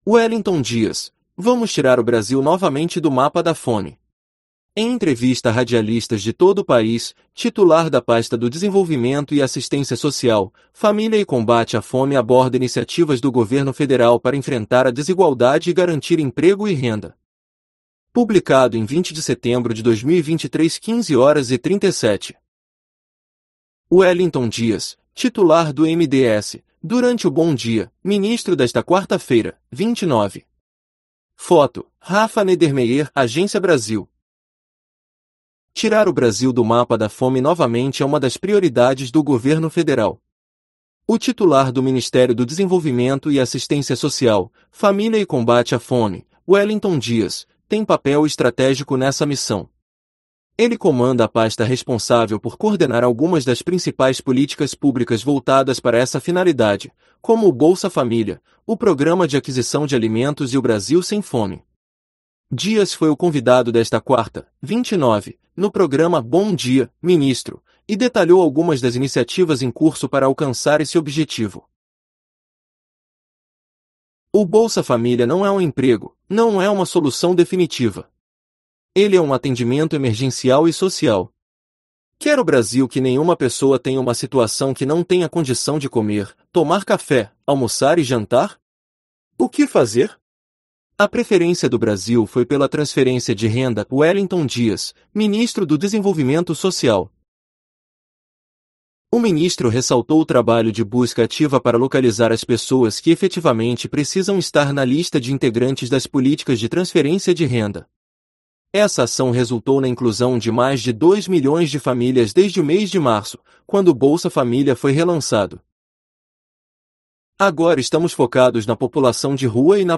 Em entrevista a radialistas de todo o país, titular da pasta do Desenvolvimento e Assistência Social, Família e Combate à Fome aborda iniciativas do Governo Federal para enfrentar a desigualdade e garantir emprego e renda